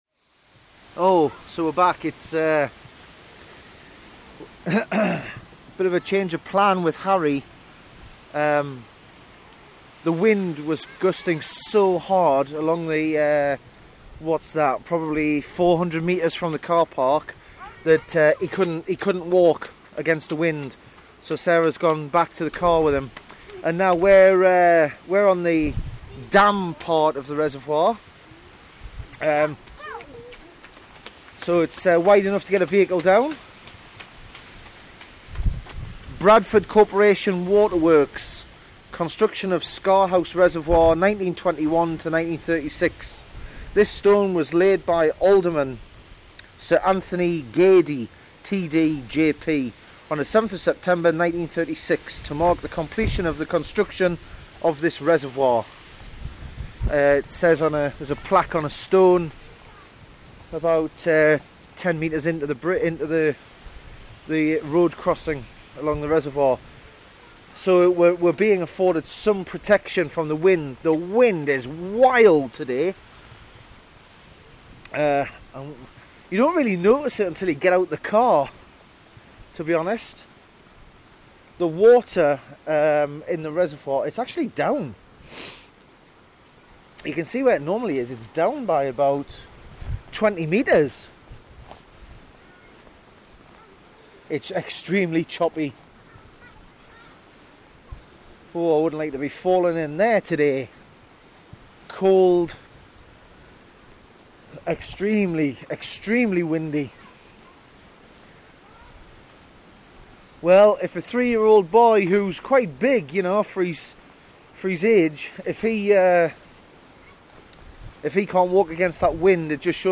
We took a trip across the beautiful Yorkshire Dales to Scar House Resevoir. It was an extremely windy day, so I apologise in advance for the 'peaking' audio.